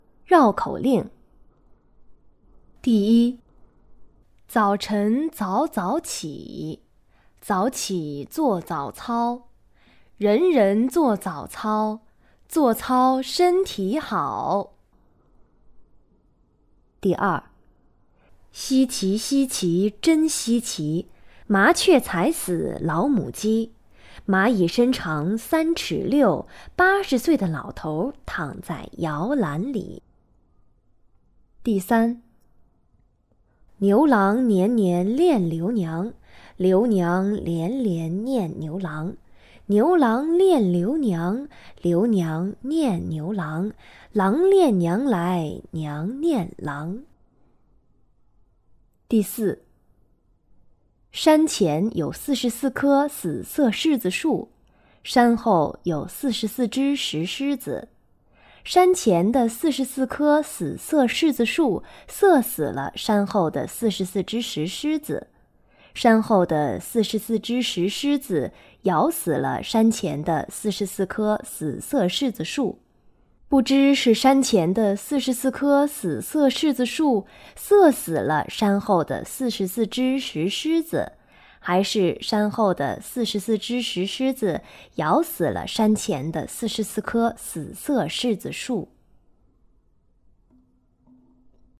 课件音频
绕口令